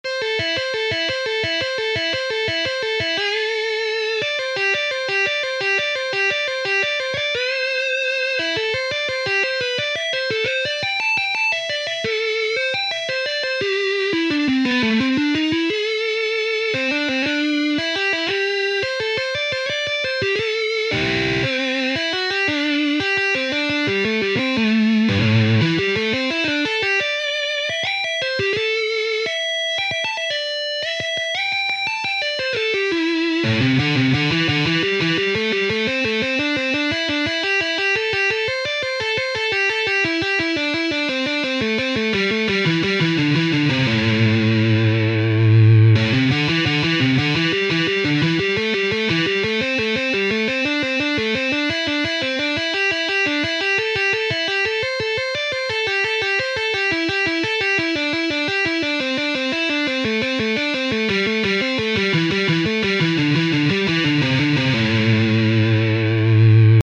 Näissä likeissä (eli siis fraaseissa) kuljetaan pitkin ja poikin pentatonista A-molliskaalaa (skaala on siis sama asia kuin asteikko ja tässä asteikossa on viisi säveltä ja pentahan on Kreikan murteella viisi). Joukossa on myös säveliä, jotka on otettu mainitun skaalan ulkopuolelta.
Paikoin saatetaan myös bendata skaalan ulkopuolisista sävelistä takaisin tasasointisempaan säveleen.
Pentatonisia ideoita #1.mp3